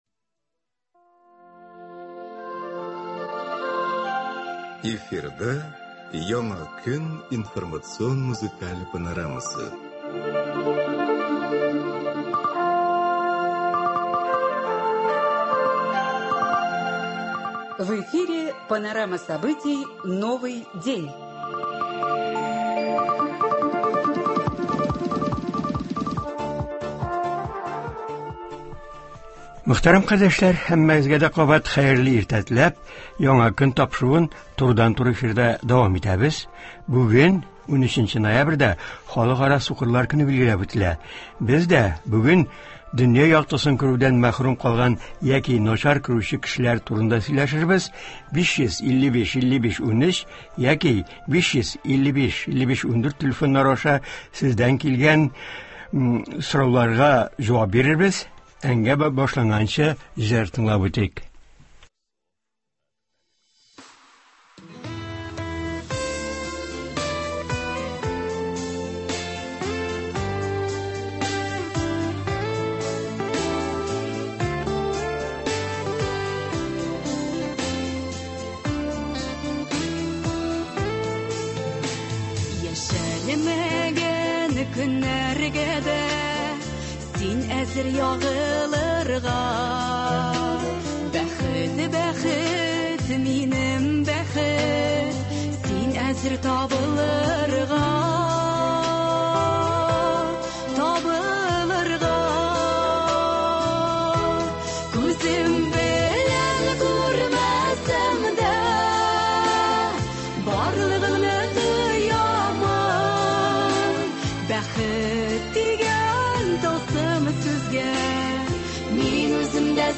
Бүген, 13 ноябрьдә, халыкара сукырлар көне билгеләп үтелә. Турыдан-туры эфирда яңгыраячак тапшыруда